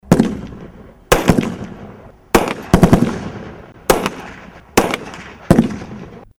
În cadrul manifestărilor premergătoare sărbătoririi Zilei de 25 Octombrie – Ziua Armatei României, militarii Garnizoanei Tirgu-Mureș au organizat, astăzi, o nouă ediţie a „Cupei Presei la TIR”.
Focuri-arma.mp3